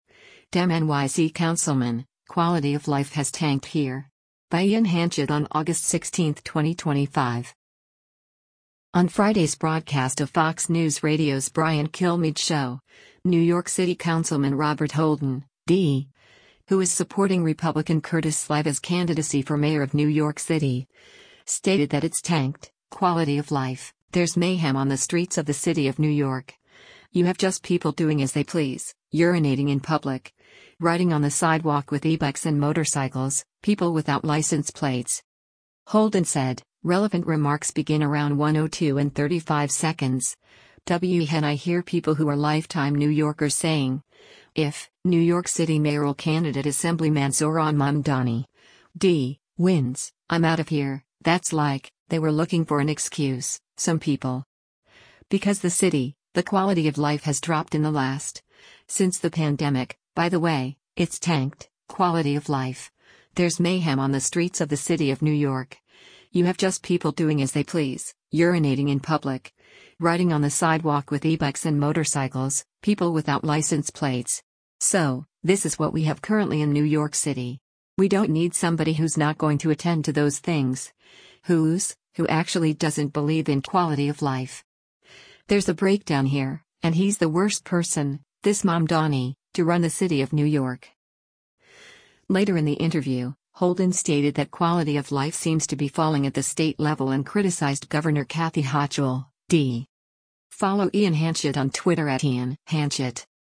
On Friday’s broadcast of Fox News Radio’s “Brian Kilmeade Show,” New York City Councilman Robert Holden (D), who is supporting Republican Curtis Sliwa’s candidacy for mayor of New York City, stated that “it’s tanked, quality of life, there’s mayhem on the streets of the city of New York, you have just people doing as they please, urinating in public, riding on the sidewalk with ebikes and motorcycles, people without license plates.”